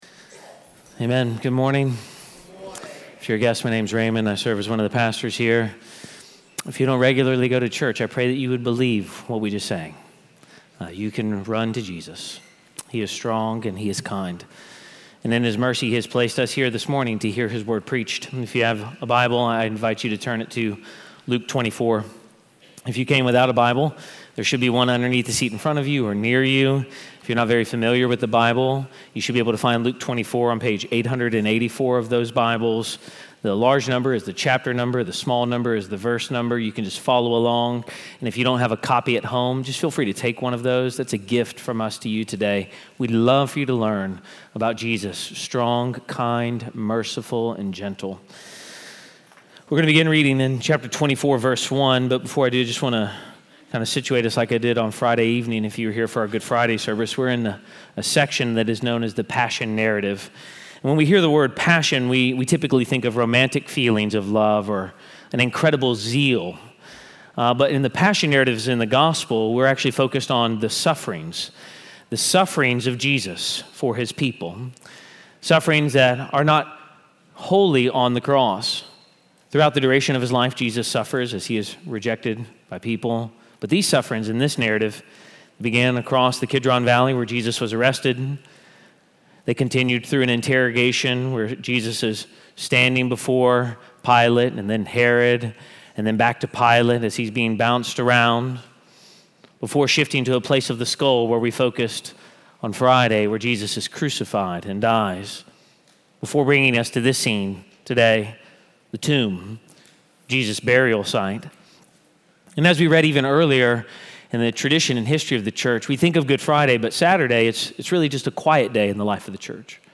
Sermon-4-5.mp3